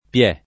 vai all'elenco alfabetico delle voci ingrandisci il carattere 100% rimpicciolisci il carattere stampa invia tramite posta elettronica codividi su Facebook piè [ p L$+ ] tronc. di piede (e di piedi ) — non pie’ (che non chiarisce dove cada l’accento) né pié (che fa pensare a una vocale chiusa) — cfr. a piè ; dappiè ; fante a piè ; piede